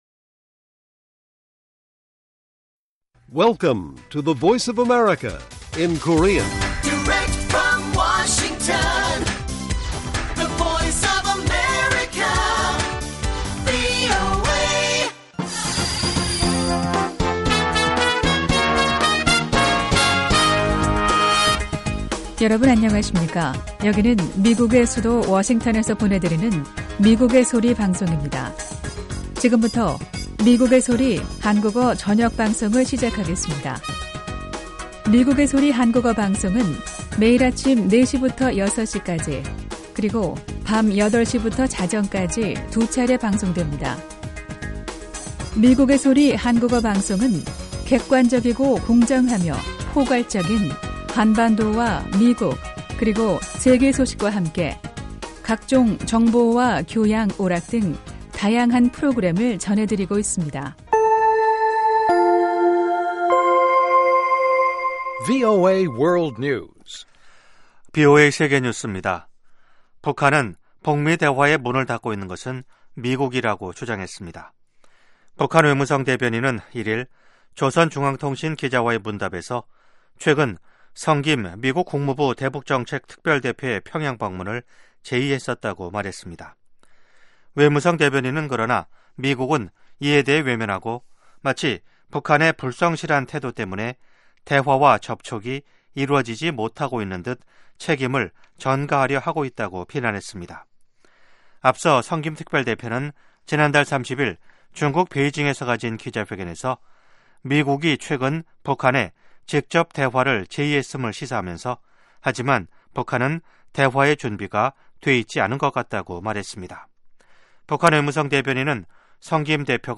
VOA 한국어 방송의 일요일 오후 프로그램 1부입니다.